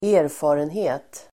Uttal: [²'e:rfa:renhe:t el. ²'ä:-]